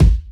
Index of /kb6/Akai_XR-20/Kick